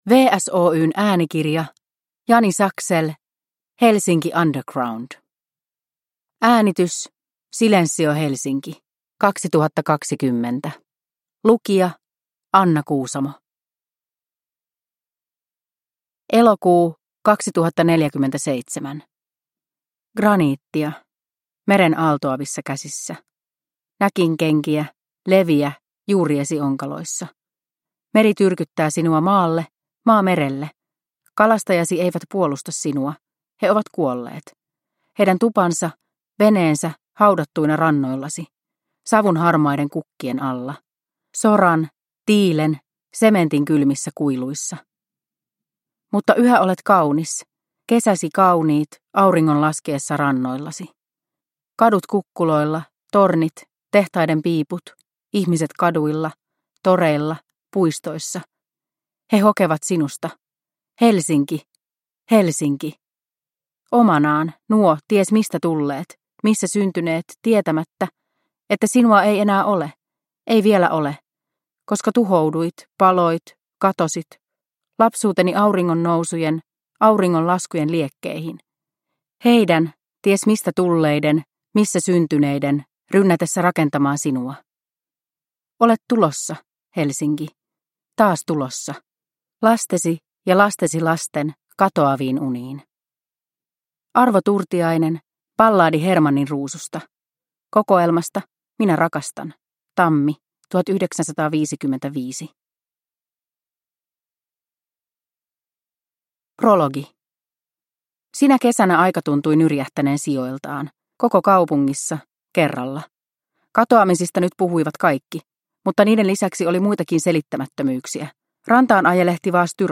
Helsinki Underground – Ljudbok – Laddas ner